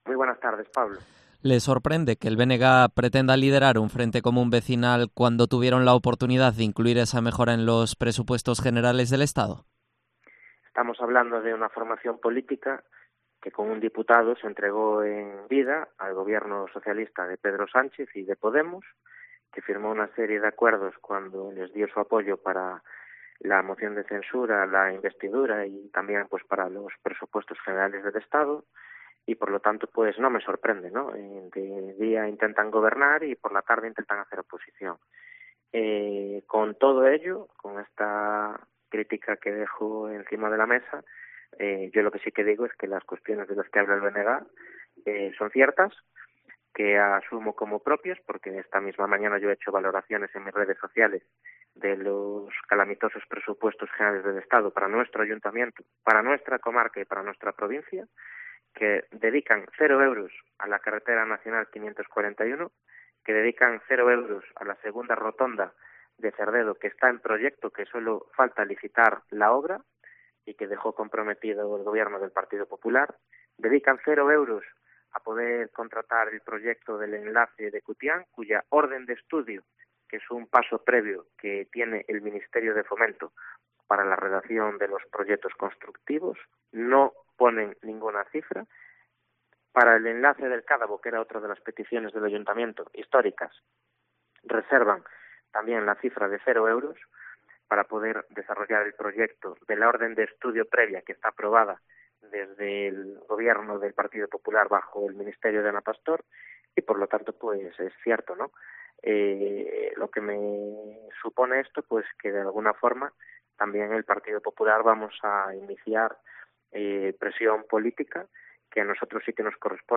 Entrevista a Jorge Cubela, alcalde de Cerdedo-Cotobade